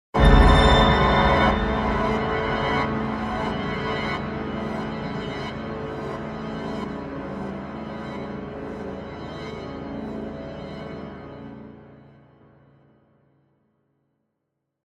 scary, spooky